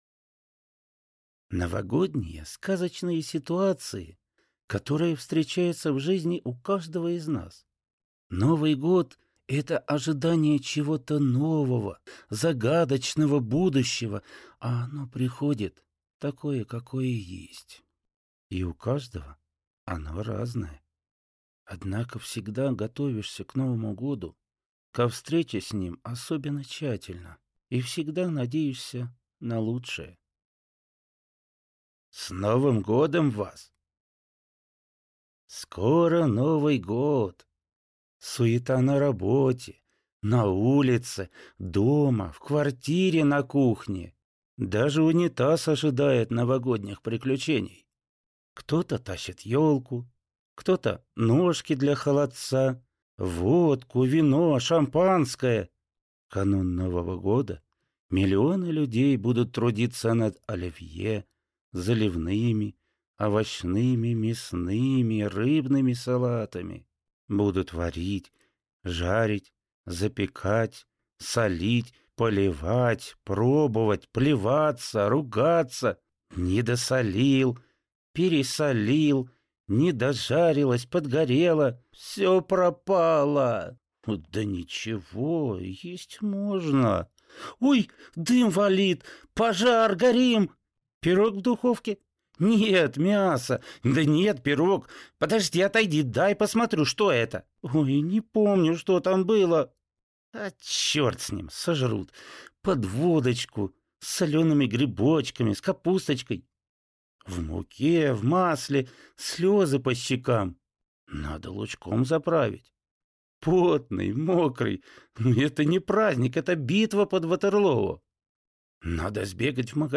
Аудиокнига Новогодние байки | Библиотека аудиокниг